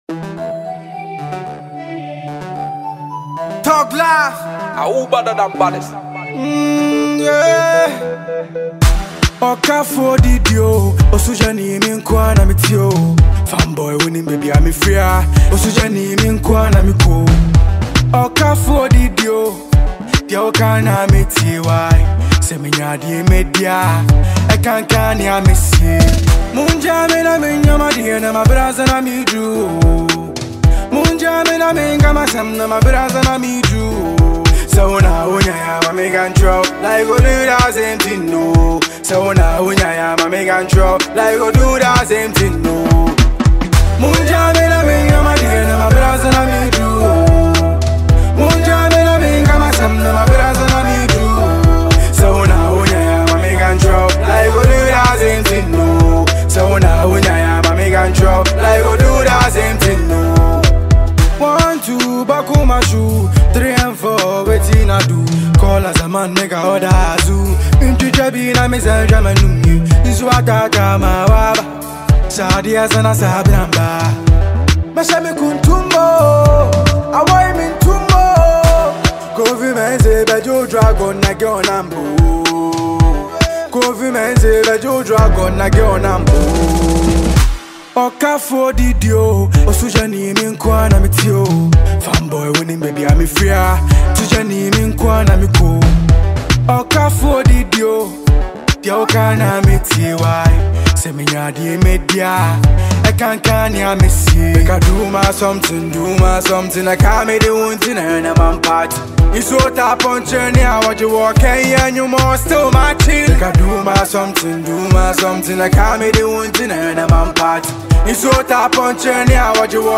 Ghana Music